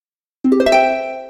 Harp.ogg